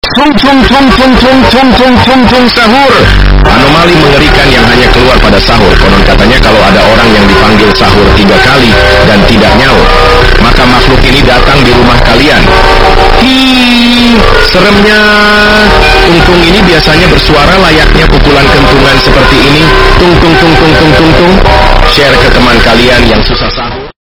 Áudio Tung Tung Tung Sahur (Versão Estendida) – Estourado
Categoria: Sons de memes
audio-tung-tung-tung-sahur-versao-estendida-estourado-pt-www_tiengdong_com.mp3